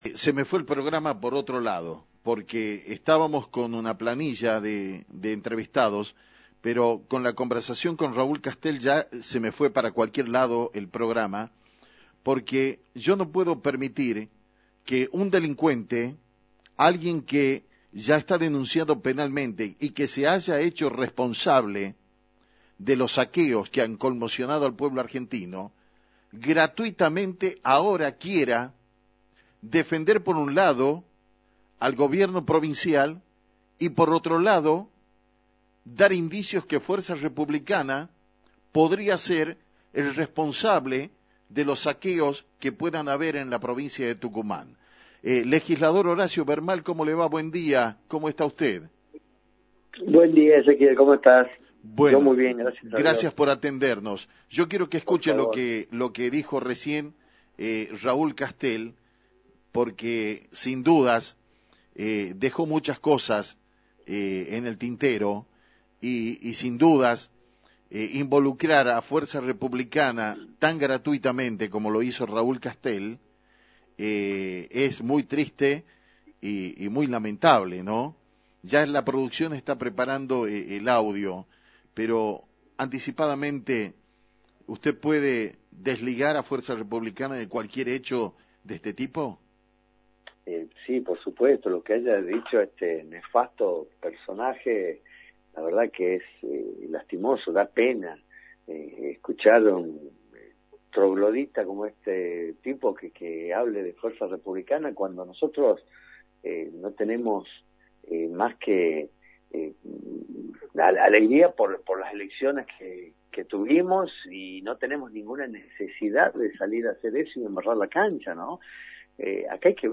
El legislador de Fuerza Republica, Horacio Vermal pasó por Metro 89.1 mhz y dialogó